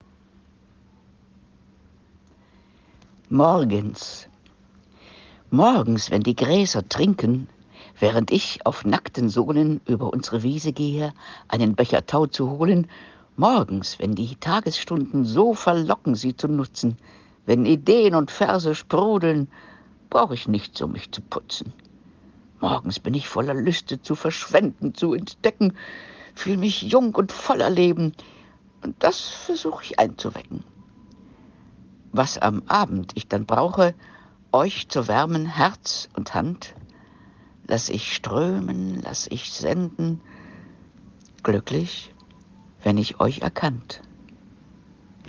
Hier werden einige Gedichte und Lyrische Texte vorgetragen, die auf der Facebookseite Gedichtezauber vorgestellt wurden.